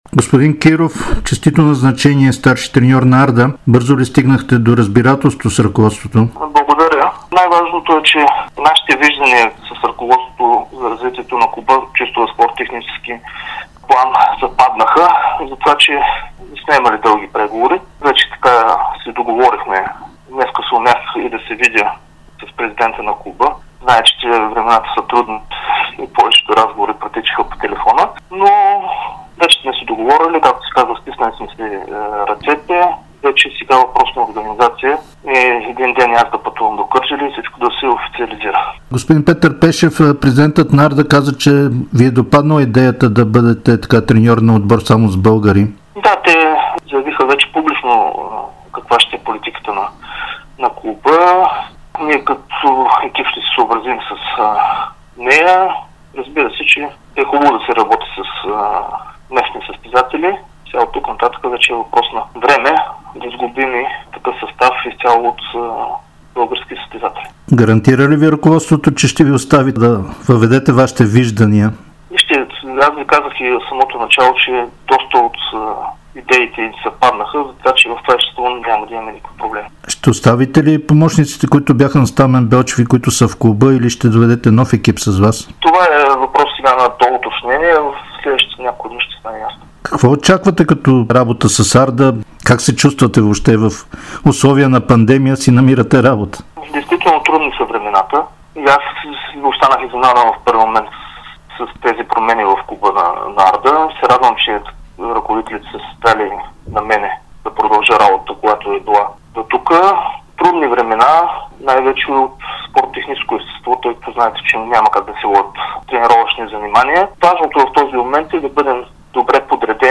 Новият старши треньор на Арда Кърджали Николай Киров заяви, че вижданията му за бъдещето на клуба са съвпаднали с тези на ръководството и това е улеснило много преговорите между двете страни. В интервю за Спортното шоу на Дарик и dsport бившият наставник на Ботев Пловдив сподели, че идеята да разчита изцяло на българи му допада, но призна, че ще са нужни големи промени поради напускането на сериозен брой играчи.